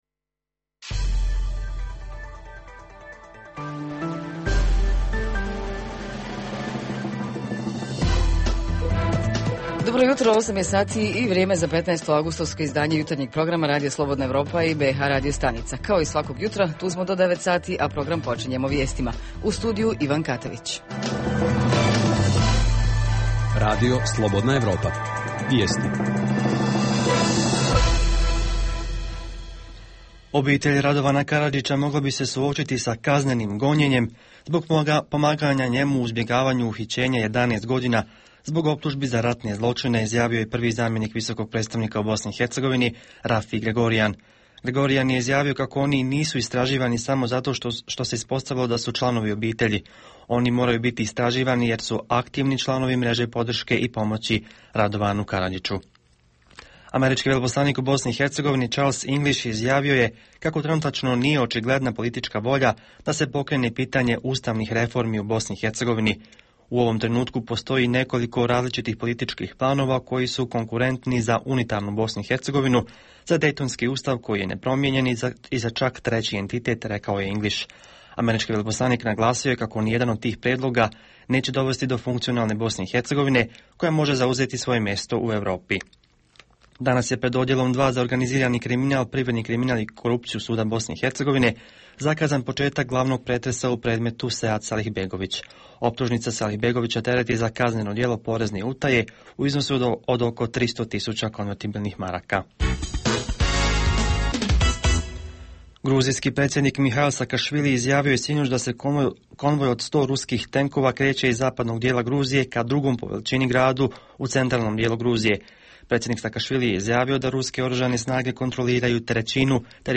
Jutarnji program za BiH koji se emituje uživo. Naši reporteri izvještavaju o kulturnim manifestacijama koje se ovog ljeta održavaju u gradovima BiH.
Redovni sadržaji jutarnjeg programa za BiH su i vijesti i muzika.